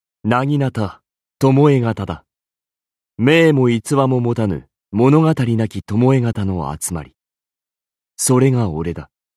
文件 文件历史 文件用途 全域文件用途 巴形入手.mp3 （MP3音频文件，总共长9.4秒，码率64 kbps，文件大小：73 KB） 巴形入手语音 文件历史 点击某个日期/时间查看对应时刻的文件。